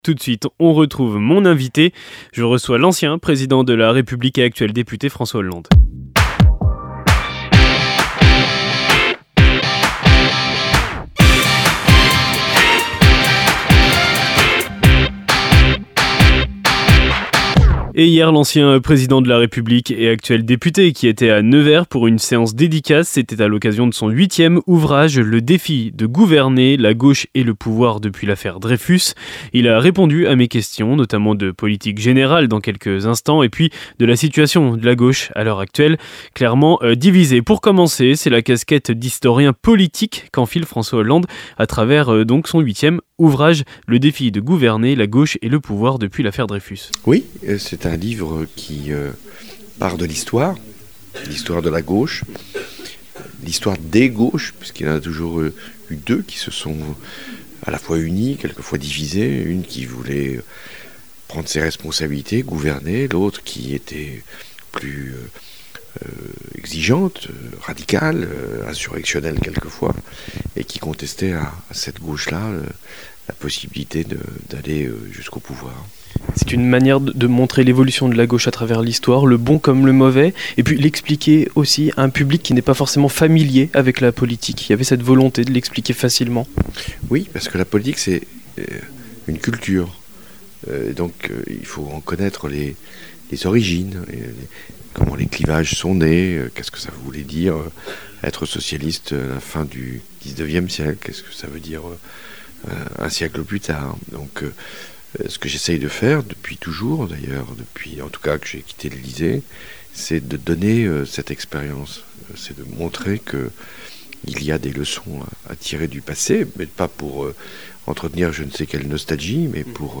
François Hollande était notre invité à l'occasion de sa venue à Nevers pour présenter son nouvel ouvrage “Le défi de gouverner”